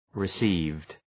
Shkrimi fonetik {rı’si:vd}